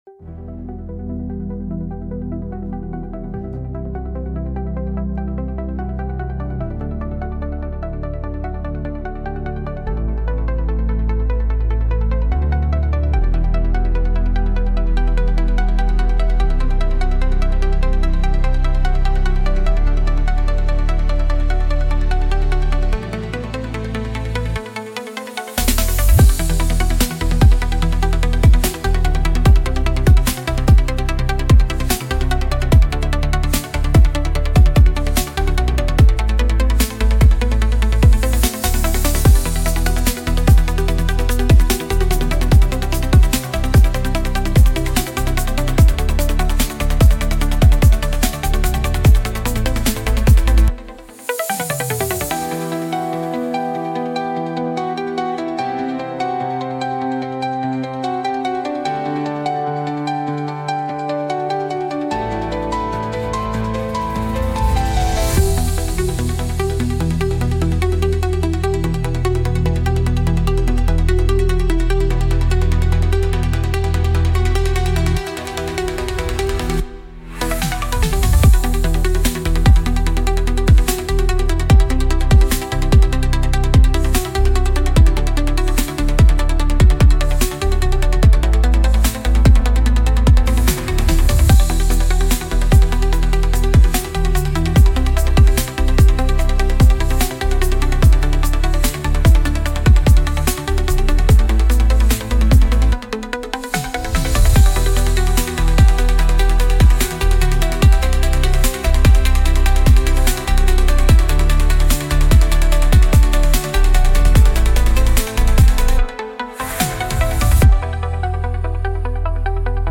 Instrumental - Heartbeat Mirage”